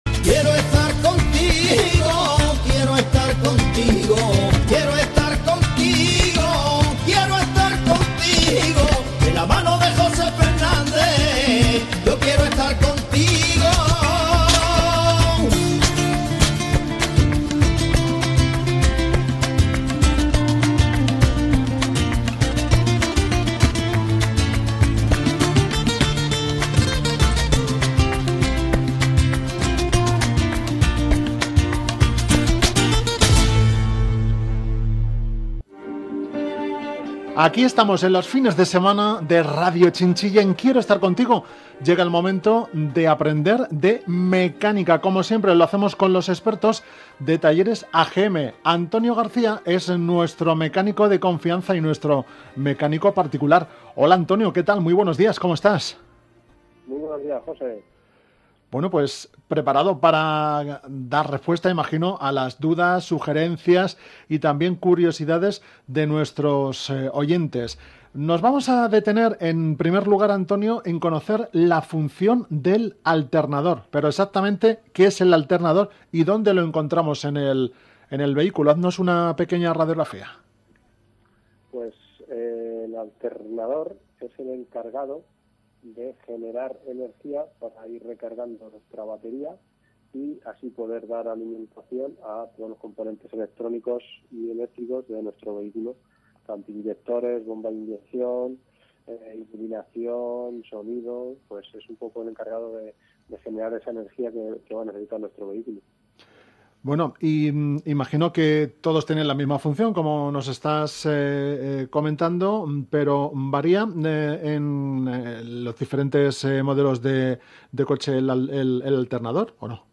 Te explicamos en esta breve entrevista en Radio Chinchilla cómo mantener el alternador de tu coche en buen estado.